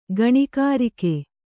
♪ gaṇikārike